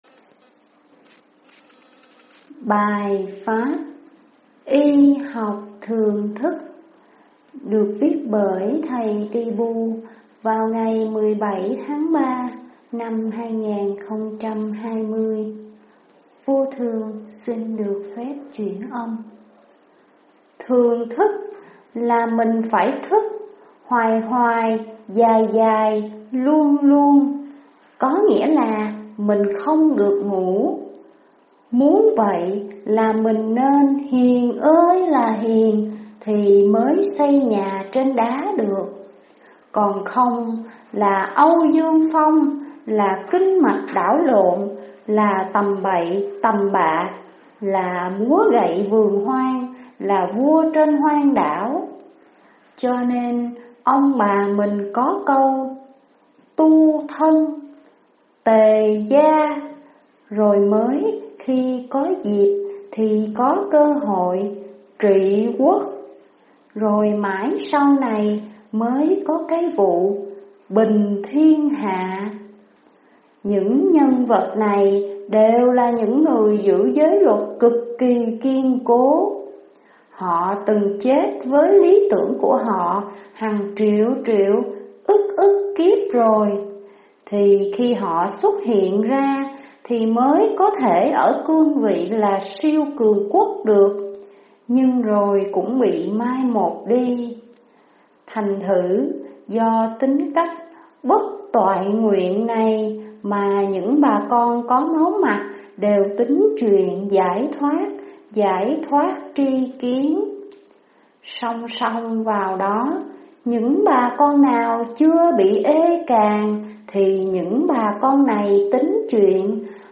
Y học thường thức (chuyển âm)